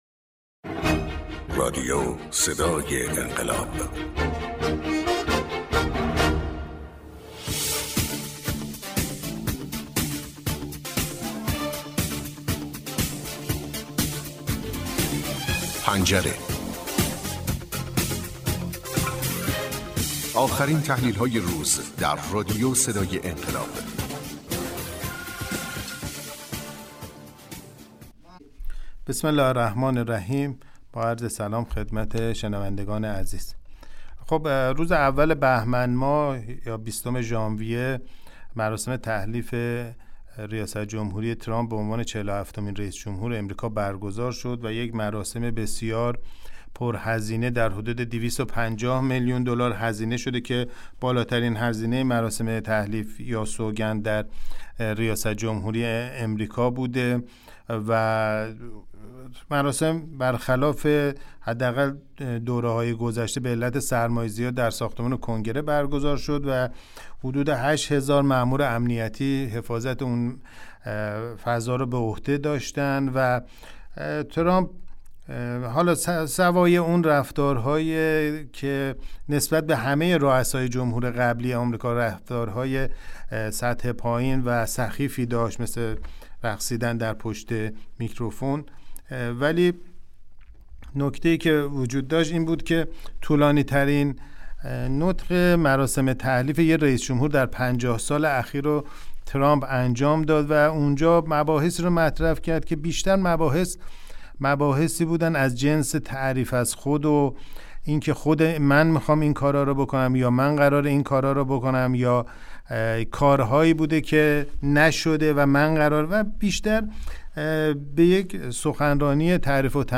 کارشناس مسائل سیاسی